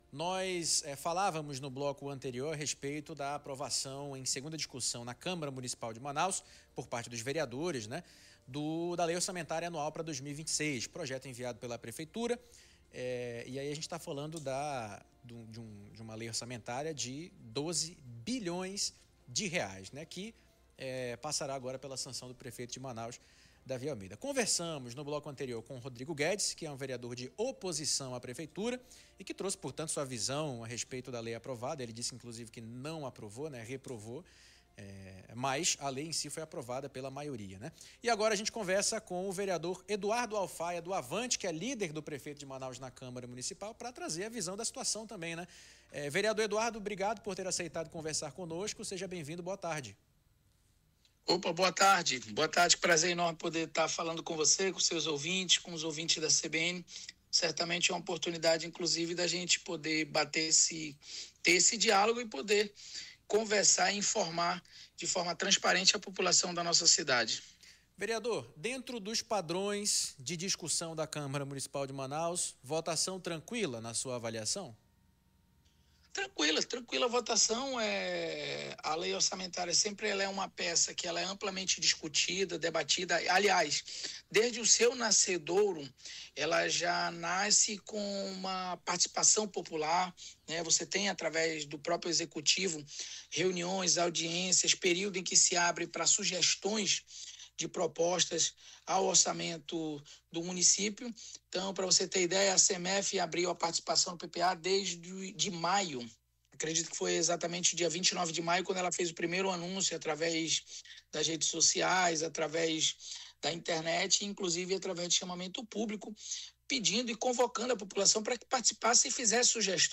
ENTREVISTA-ALFAIA.mp3